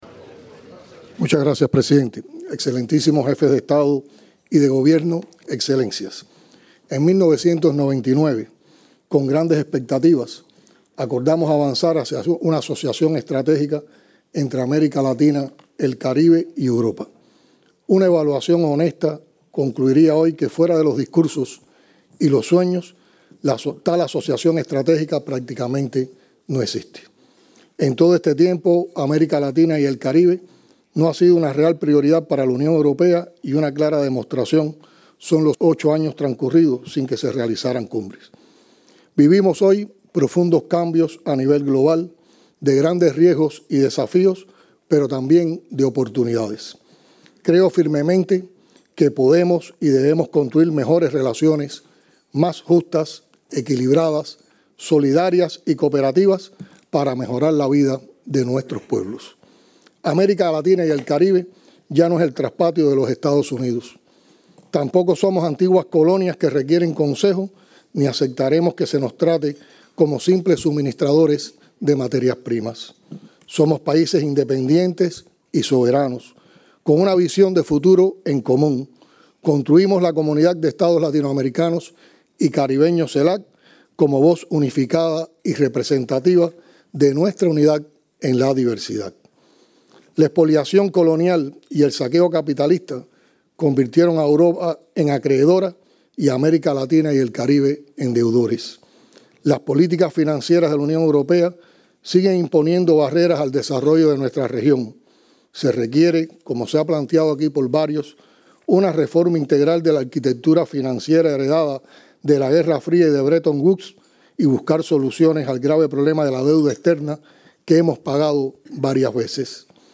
Intervención de Miguel Díaz-Canel Bermúdez, primer secretario del Comité Central del Partido Comunista de Cuba y presidente de la República, en la III Cumbre CELAC-UE en Bruselas, Bélgica, el 18 de julio de 2023.